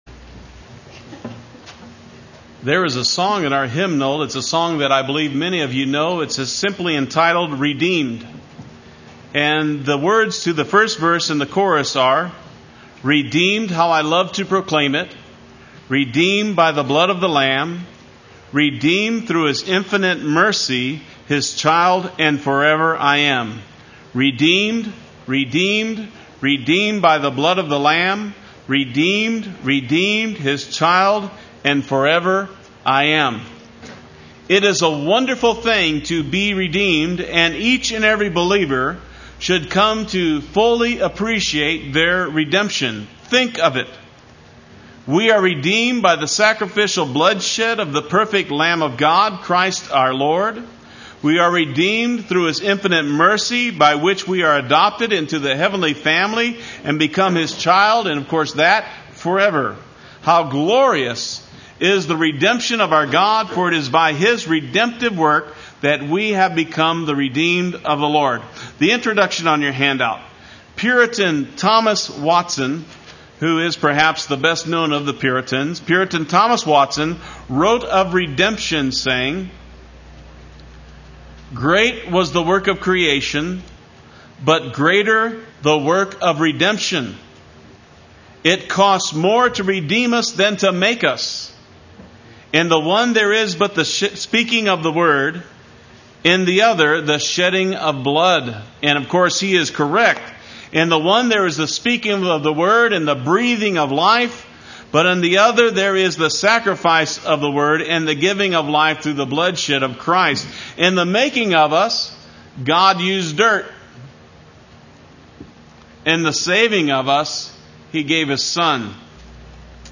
Play Sermon Get HCF Teaching Automatically.
Redeemed Sunday Worship